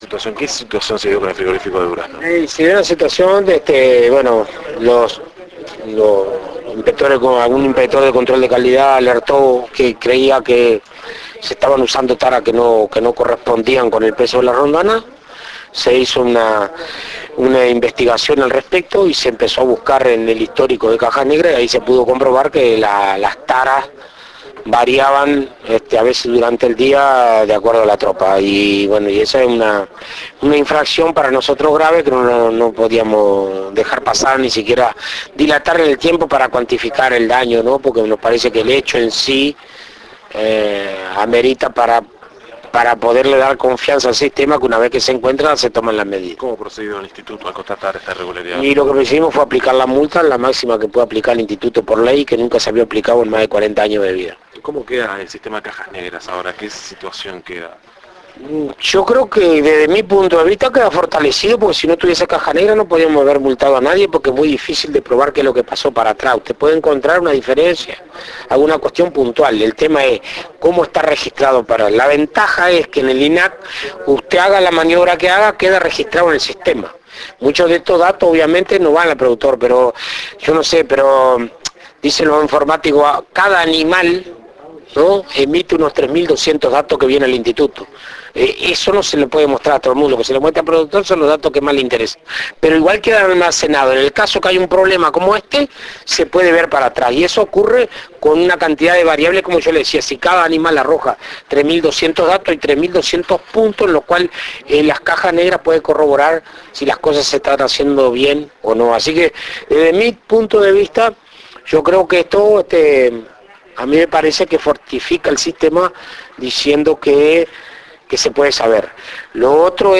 En conferencia de prensa, el Dr. Alfredo Fratti sostuvo que fue un inspector de Control de Calidad quien detectó una irregularidad en las taras, situación que luego fue corroborada por el sistema de cajas negras.
Presidente de INAC. mp3. 3:31